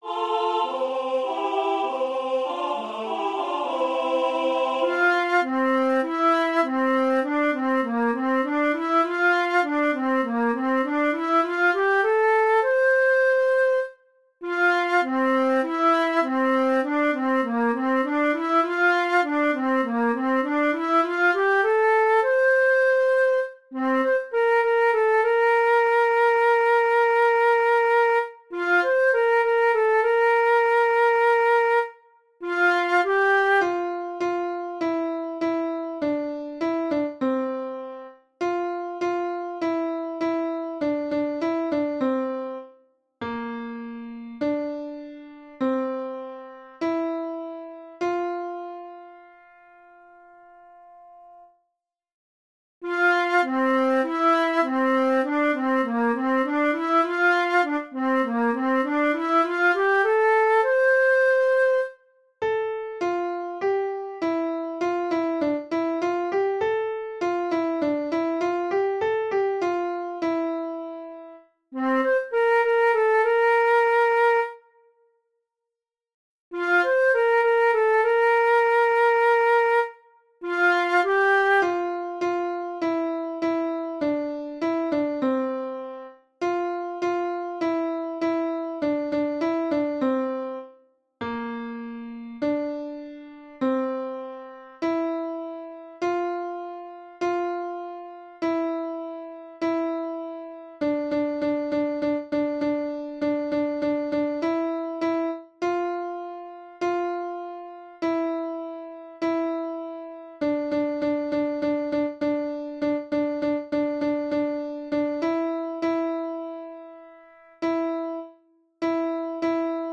Choir
Things highlighted blue are the high parts sung by the group of three children